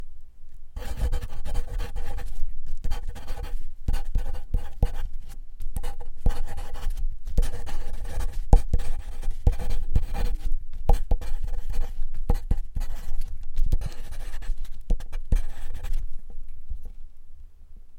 写作 " 铅笔在玻璃上的纸 2
描述：记录在带有SM81和便宜的akg SDC的SD 702上，不记得哪一个只是想要变化。
没有EQ不低端滚动所以它有一个丰富的低端，你可以驯服品尝。